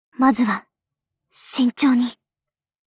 Cv-30608_warcry.mp3